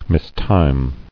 [mis·time]